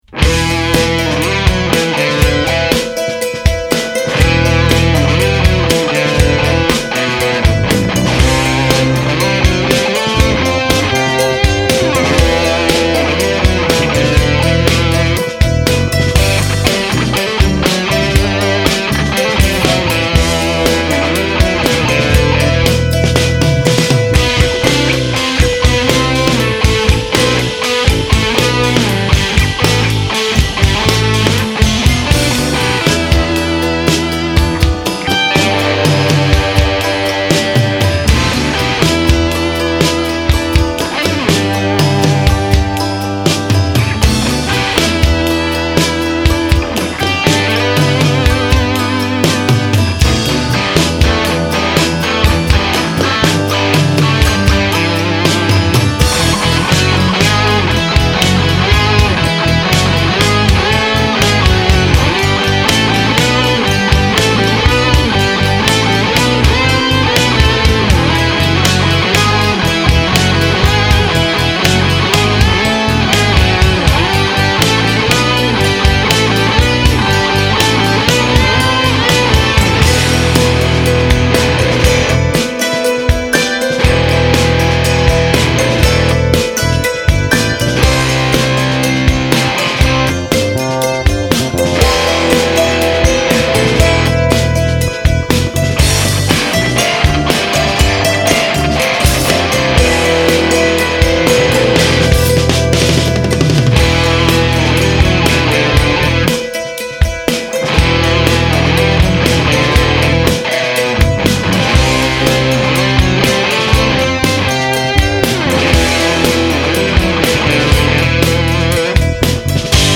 I wrote Ode to Soup on the hammer dulcimer in 2004.
It’s a great sonic accompaniment to the dulcimer part, a couple octaves lower and more legato than percussive in style.
We recorded the guitar tracks — three parts times 2-4 takes apiece, in stereo — at my home studio over Thanksgiving weekend, 2004.
Drums, Dulcimer
Bass
Guitars